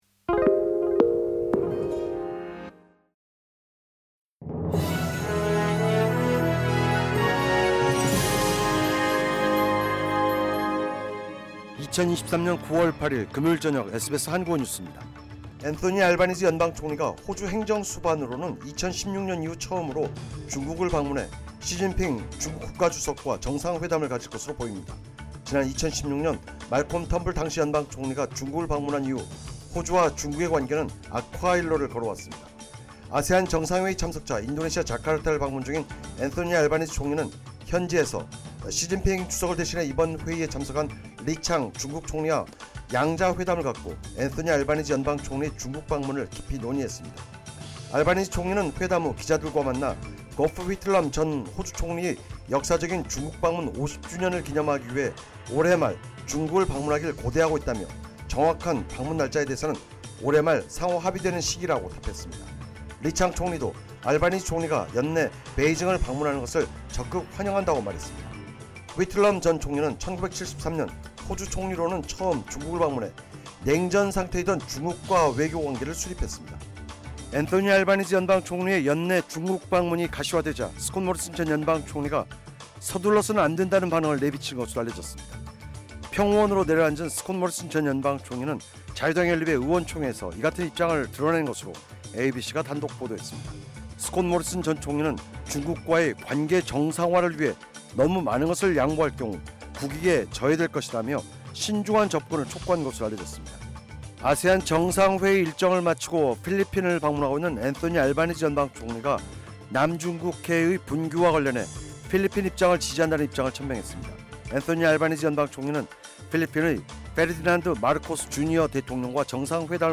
SBS 한국어 저녁 뉴스: 2203년 9월8일 금요일
2023년 9월 8일 금요일 저녁 SBS 한국어 뉴스입니다.